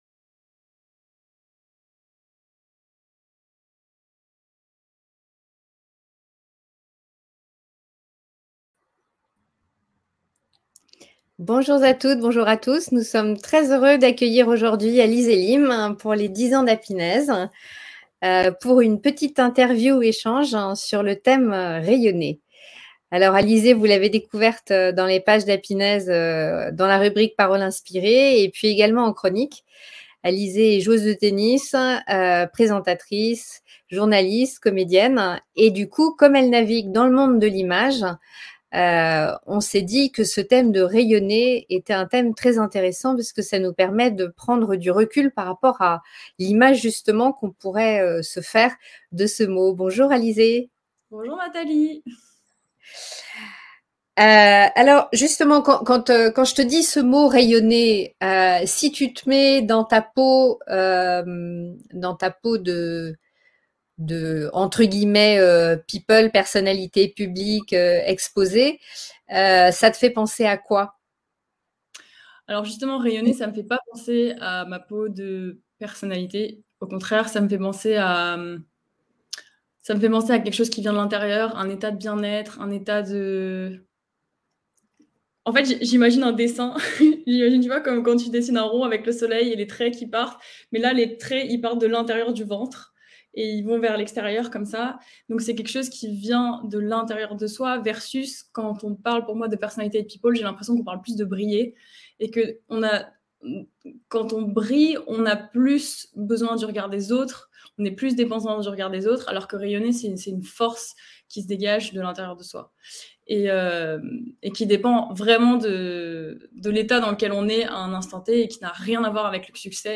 Interview 10 ans - Rayonner